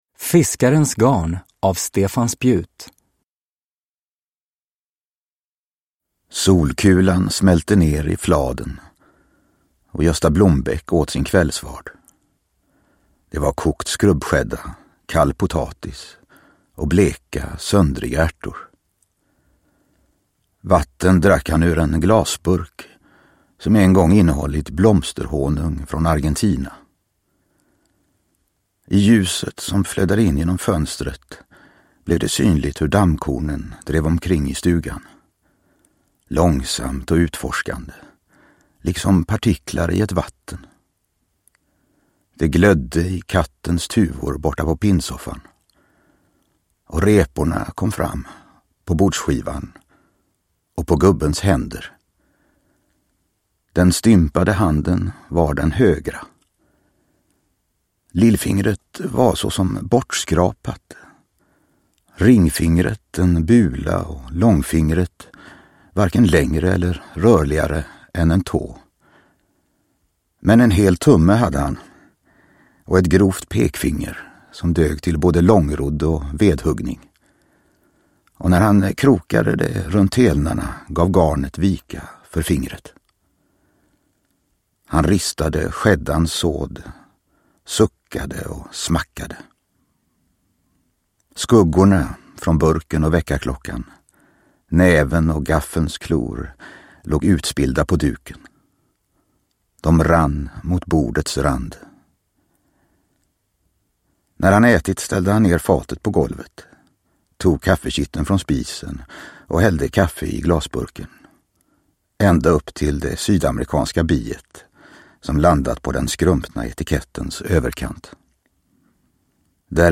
Nedladdningsbar Ljudbok